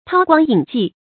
韜光隱跡 注音： ㄊㄠ ㄍㄨㄤ ㄧㄣˇ ㄐㄧˋ 讀音讀法： 意思解釋： 同「韜光晦跡」。